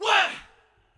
Whaat.wav